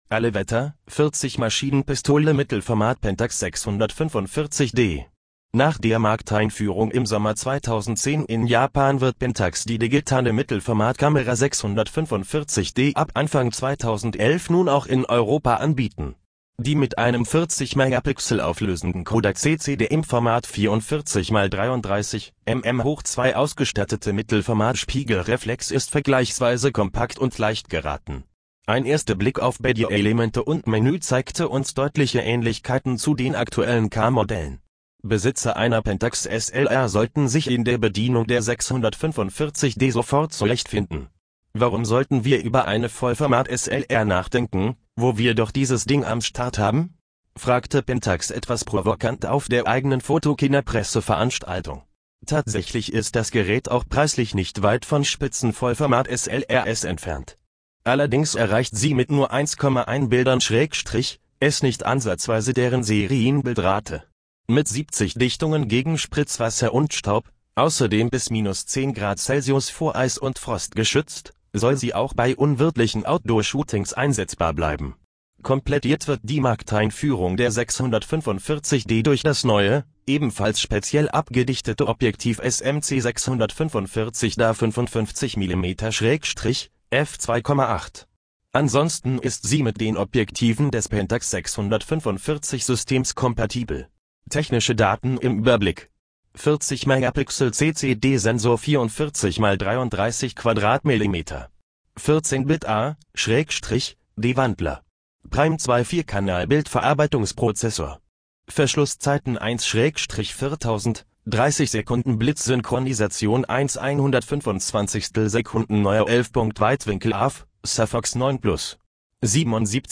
Für alle, die es interessiert: Die Software hatte damals die "MP" (=MegaPixel) im Titel nicht als "MP" vorgelesen, sondern als "Maschinen-Pistolen".
Sie müssen sich nur die Headline vorlesen lassen und Sie werden Zeuge einer technischen Stilblüte, wie sie sicherlich vieler Orts zu finden wäre, wenn man nur einmal genauer hinsieht ... oder - wie in diesem Fall - hinhört.